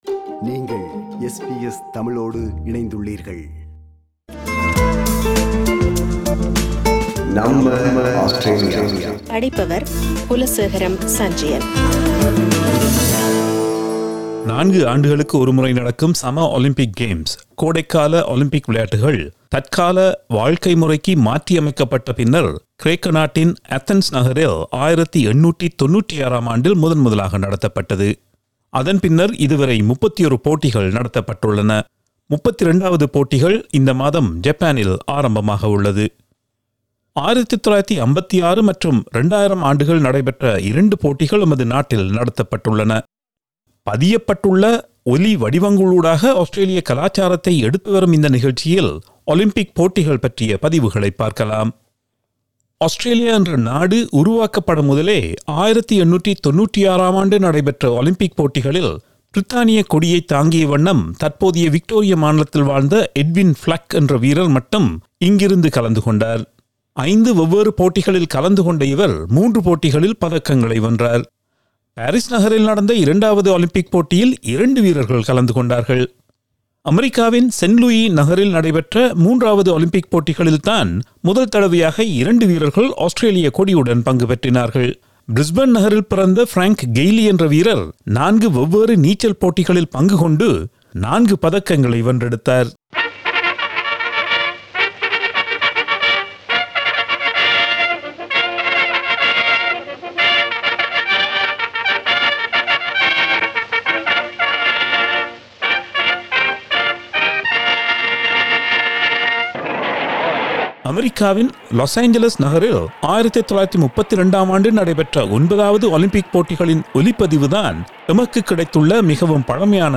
பதியப்பட்டுள்ள ஒலி வடிவங்களூடாக ஆஸ்திரேலிய கலாச்சாரத்தை எடுத்து வரும் இந்த நிகழ்ச்சியில் ஒலிம்பிக் போட்டிகள் பற்றிய பதிவுகளைப் பார்க்கலாம்.